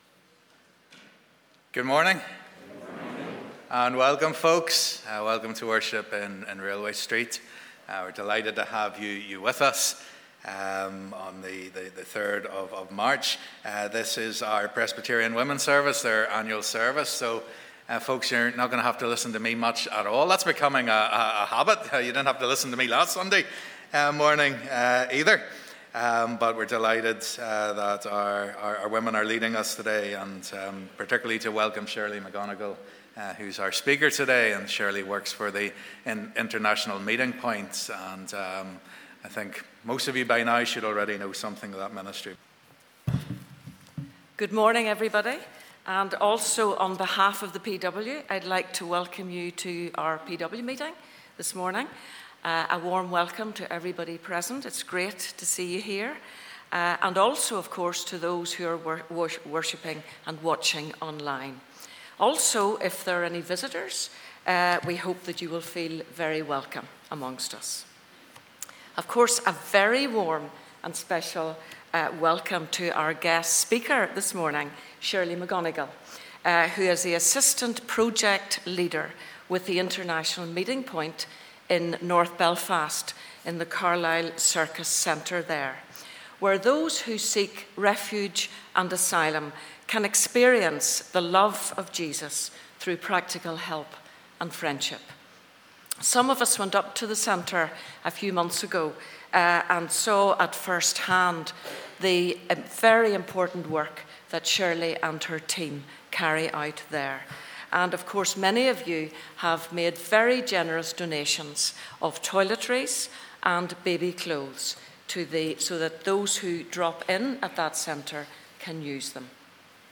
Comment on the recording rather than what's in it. Welcome today to our annual Presbyterian Women service in RSPC.